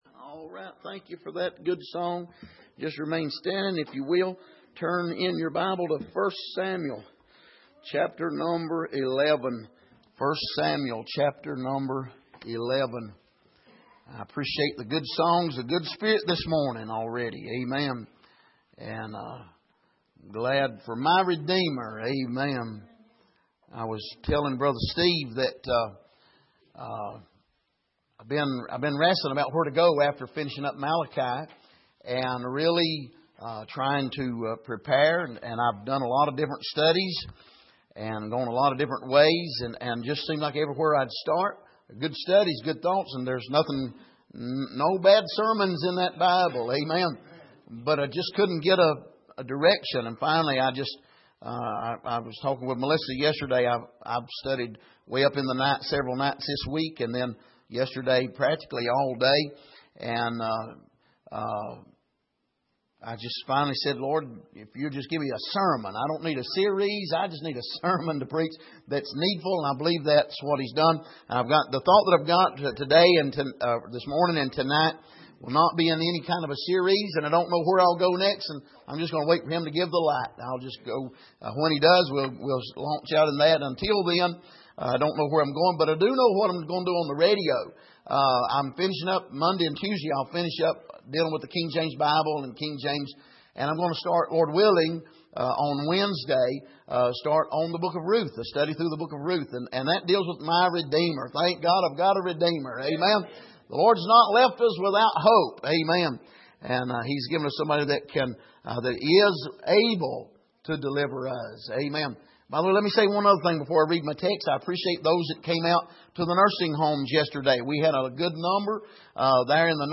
Passage: 1 Samuel 11:1-4 Service: Sunday Morning